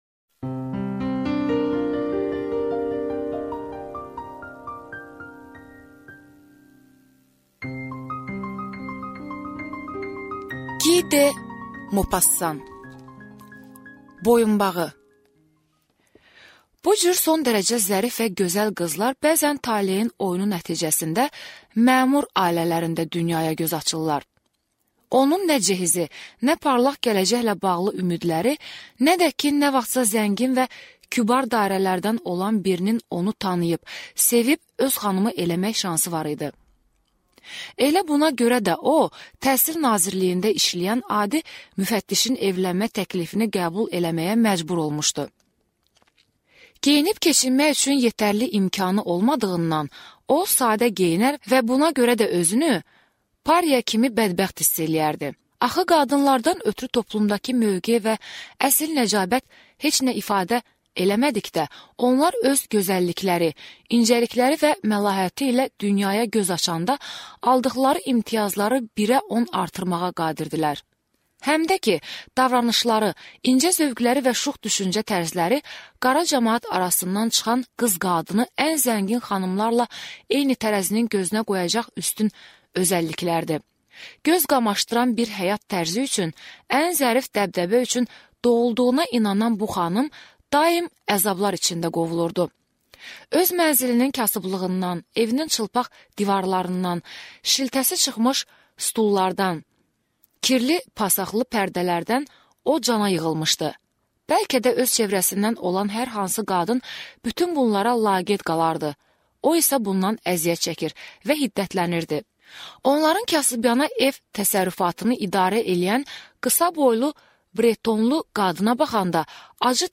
Аудиокнига Boyunbağı | Библиотека аудиокниг